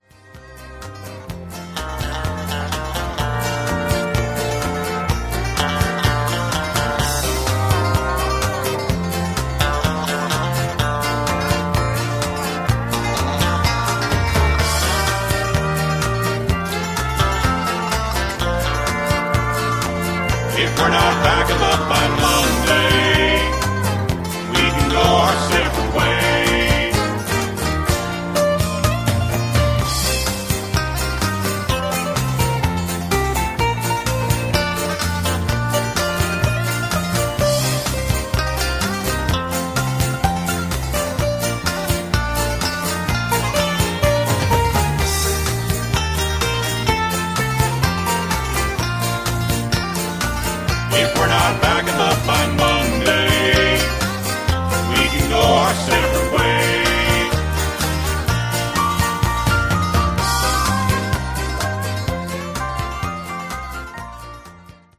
Instrumental with BGVs